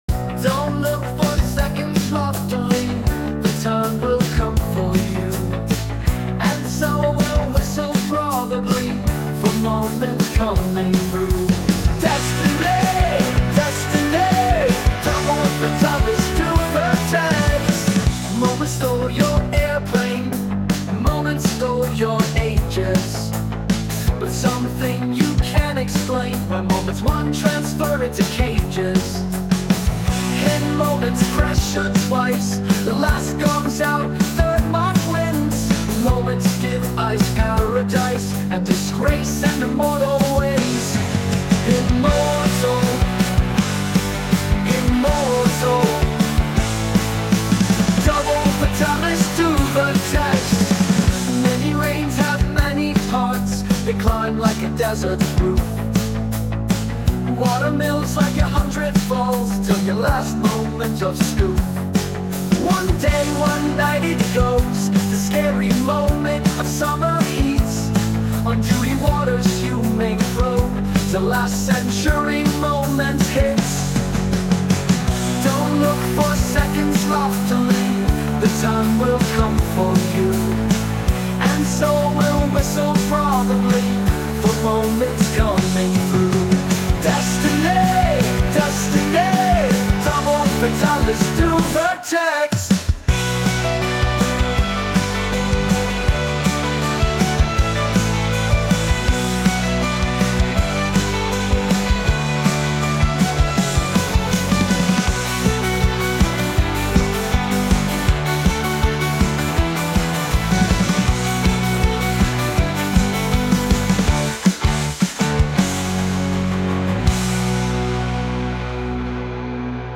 ещё один удачный нейроген (индирок), как по мне.